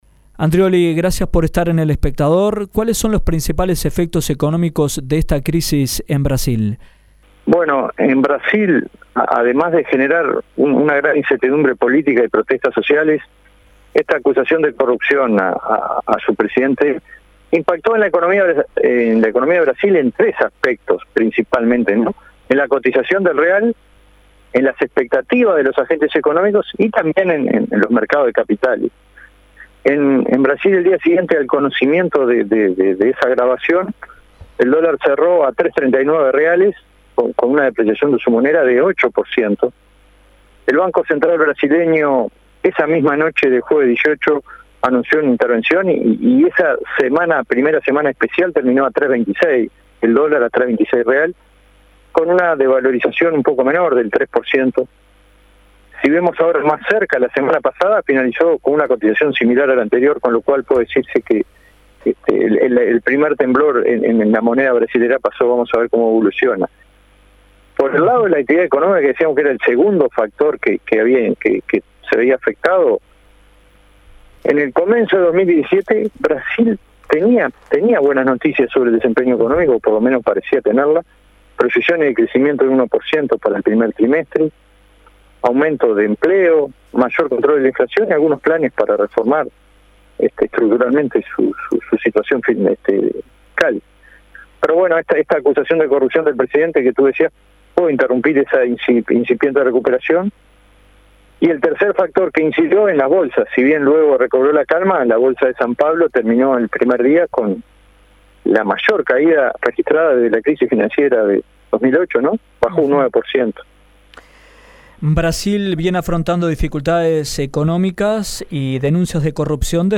Analisis: efecto de crisis en Brasil